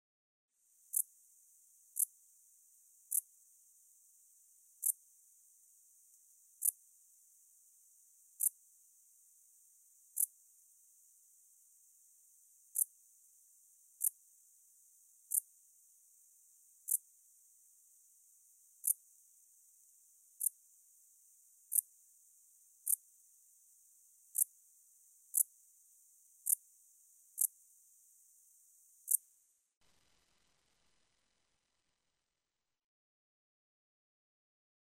Buskgræshoppe - Pholidoptera griseoaptera
Art|Insekter
buskgræshoppe.mp3